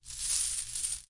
描述：硬币之间的硬币碰撞
Tag: 碰撞 钱币 monedas